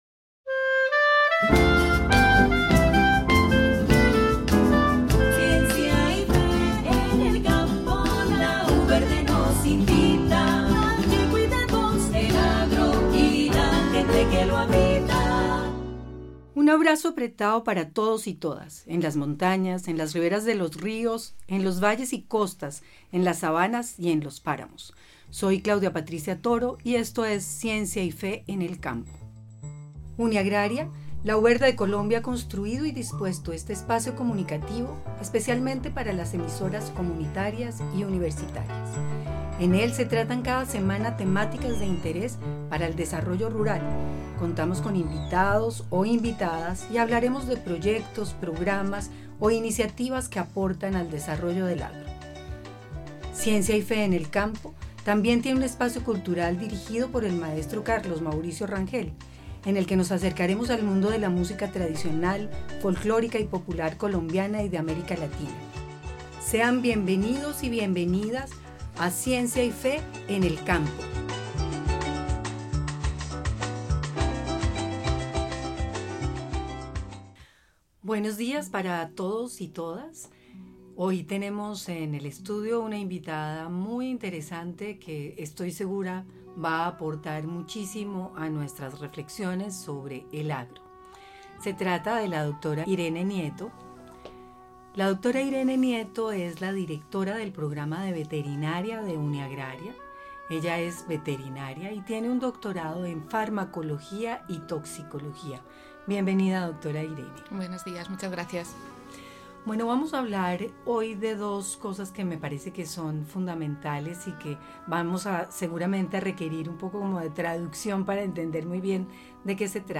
Invitada especial